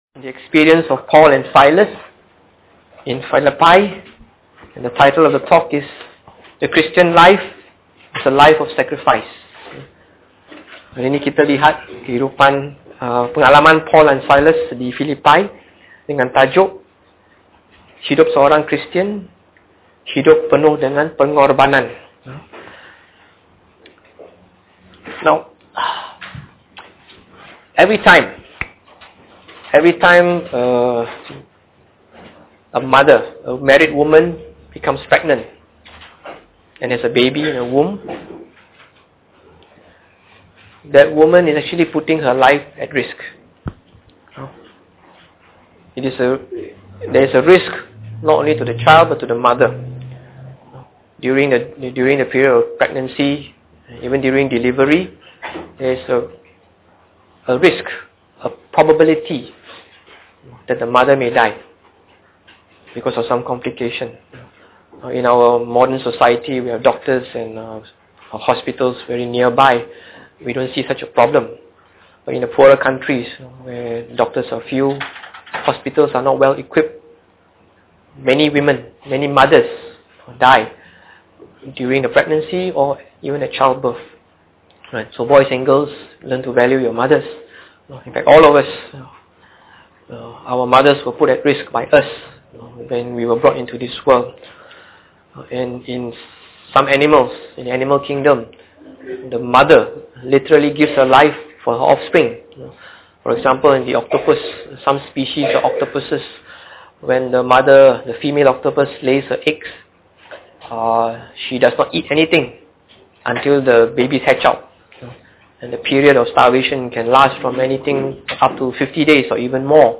Preached on the 27th of April 2008.